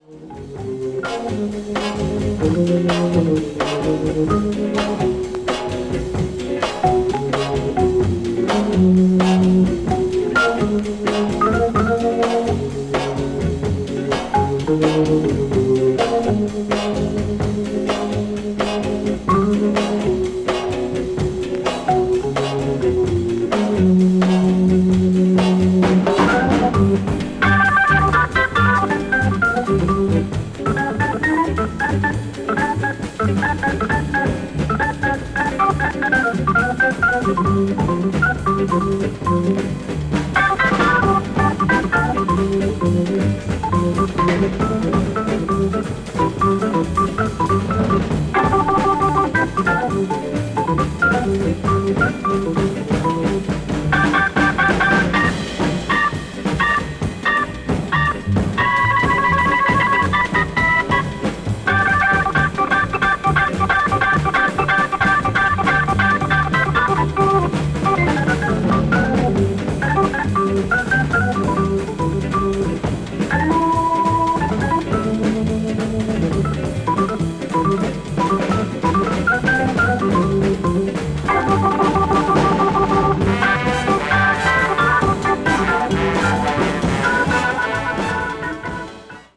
This album features orchestral arrangements of popular songs
jazz standard   modern jazz   organ jazz   soul jazz